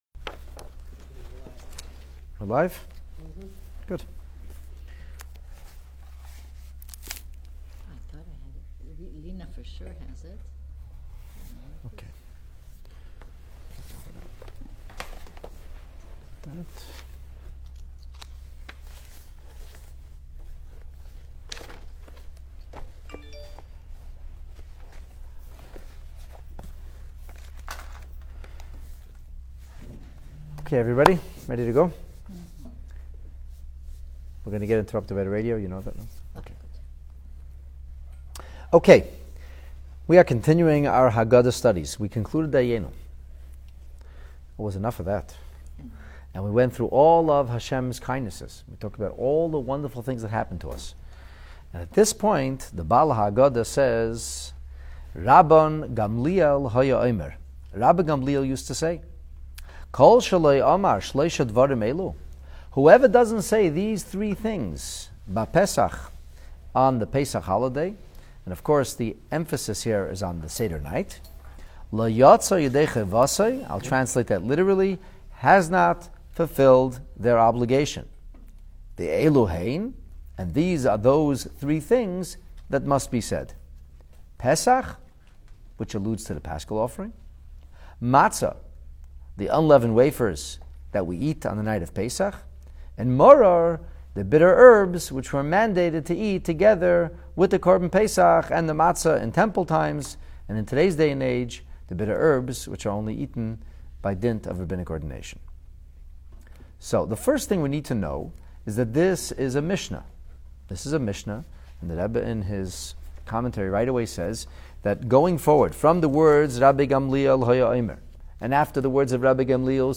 Now, our Seder Handbook introduces us to a critical component of the parental duty to recount our saga of national liberation; verbalizing the motifs behind Passover’s iconic items. This foundational class introduces and explores the origins, scope, rationale and purposes of this mandated articulation about these proverbial Pesach holiday essentials.